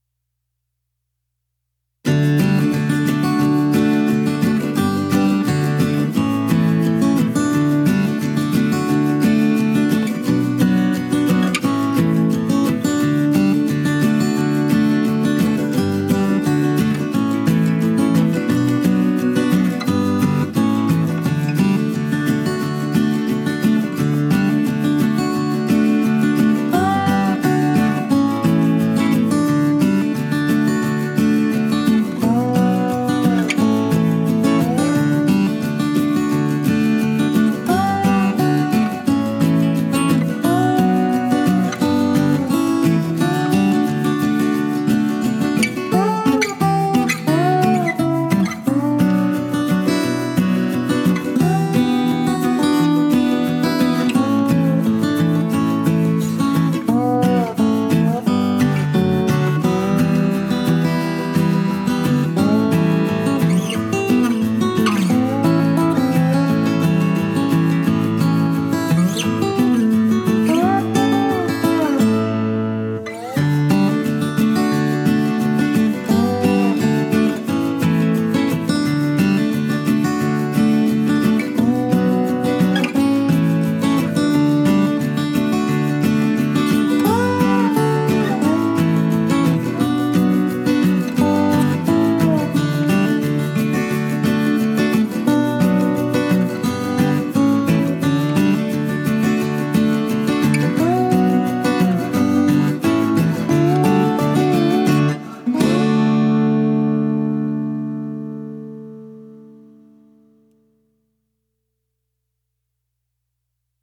Мелодия для рекламы без авторских прав